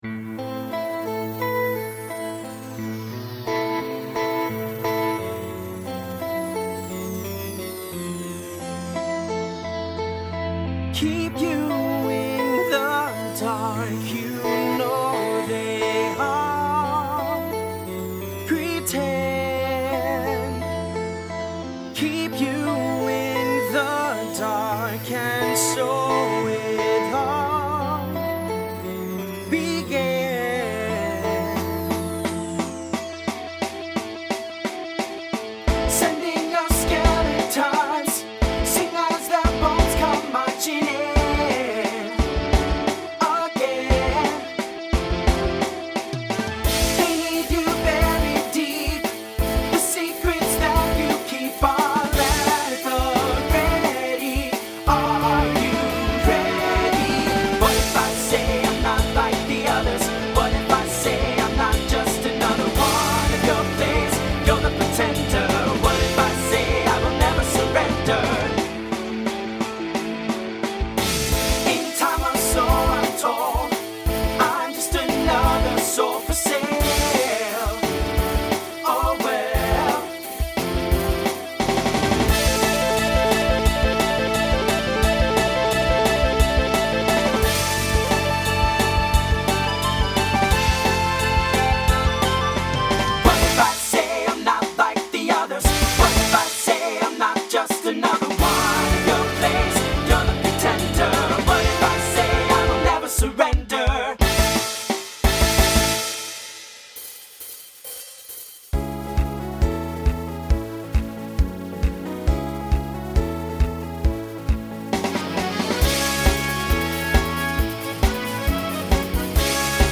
TTB/SSA
Instrumental combo
Pop/Dance